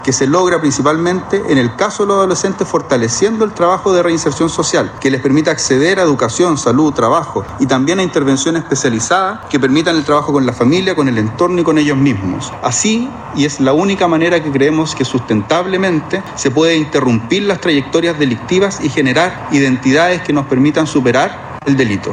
A su vez, el ministro de la cartera, Jaime Gajardo, en su discurso -ya que no habló con la prensa- destacó que, emparejado con la preocupación ciudadana relativa a la seguridad pública con los adolescentes infractores de la ley, se debe fortalecer el trabajo de reinserción social.